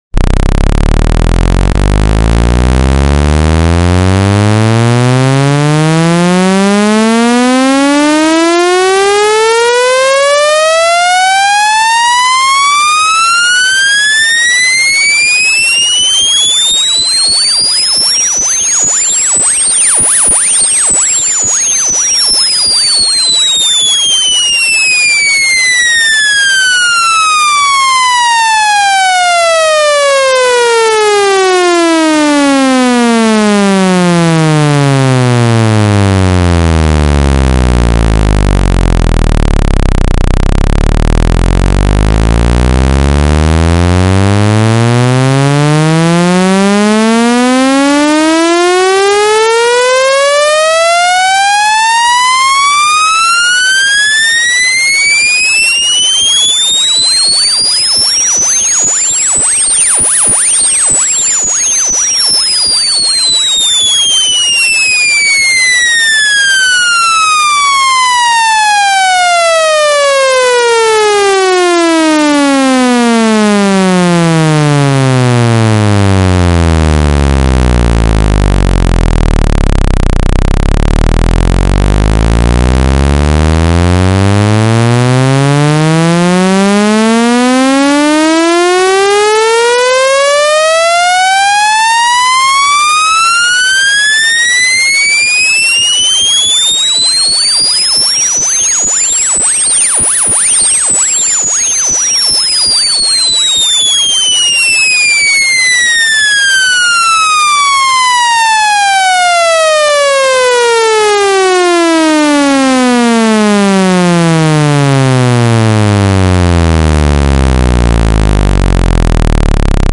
speaker cleaner solution hp you sound effects free download
speaker cleaner solution hp you are in the bathroom: v (full volume)